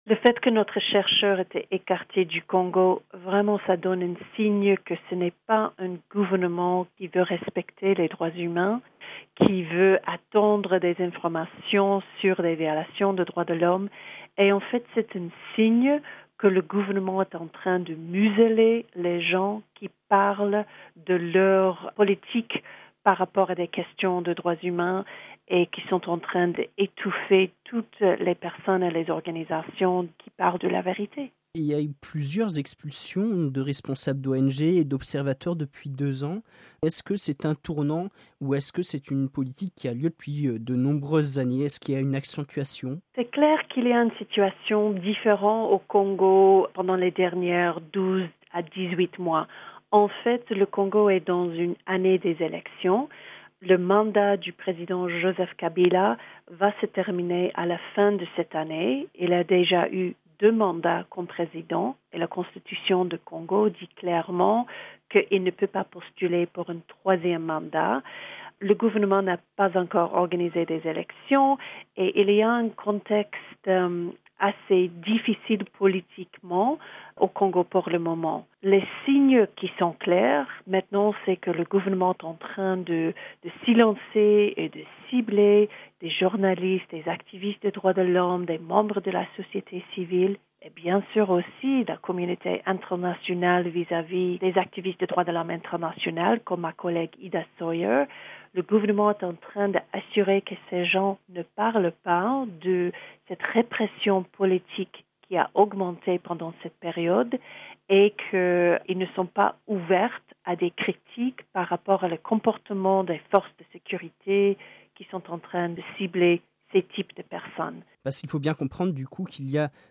(RV) Entretien - Quel avenir pour les ONG de défense des droits de l’homme en République démocratique du Congo ?